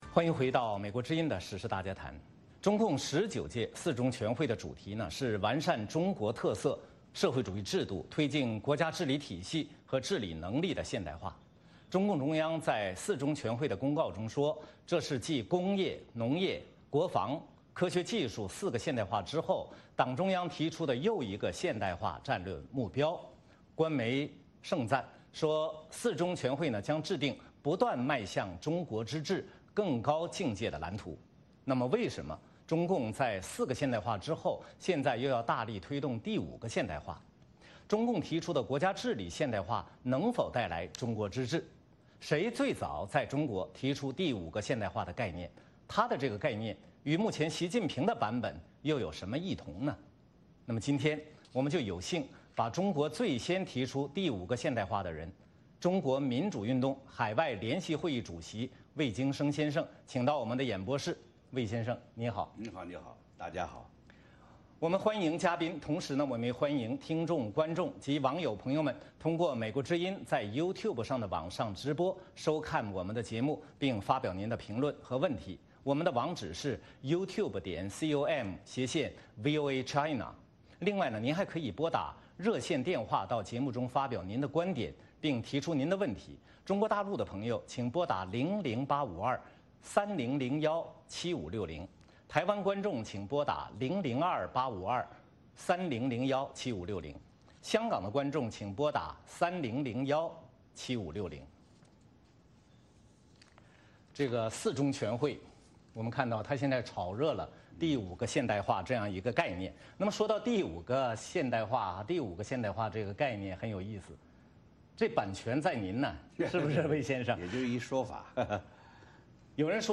美国之音时事大家谈节目有幸把中国最先提出第五个现代化的人，中国民主运动海外联席会议主席魏京生请到我们的演播室做专访。